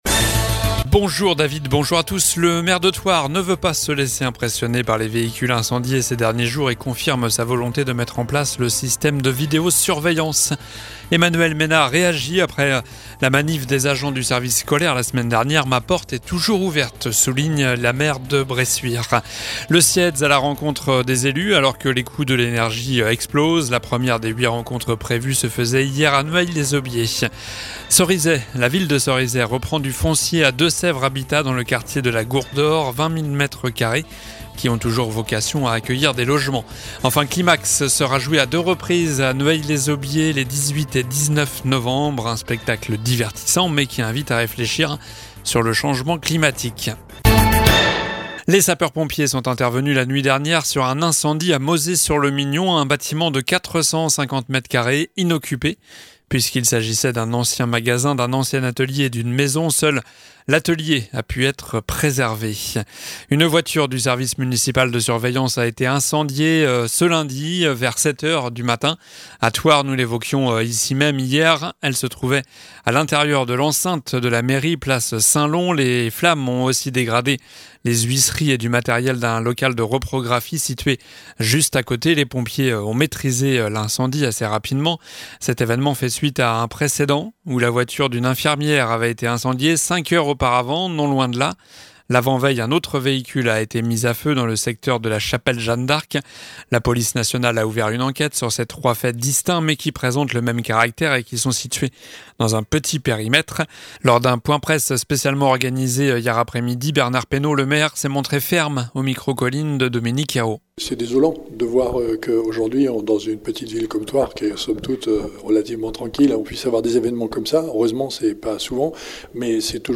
Journal du mardi 11 octobre (soir)